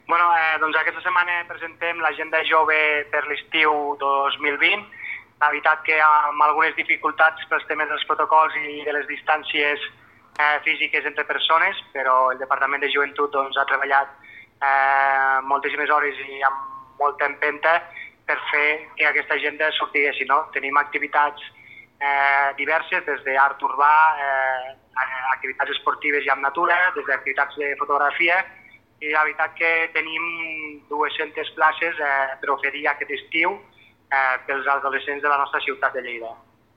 tall-de-veu-del-regidor-de-joventut-festes-i-tradicions-ignasi-amor-sobre-la-nova-agenda-jove-estiu-2020